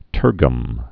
(tûrgəm)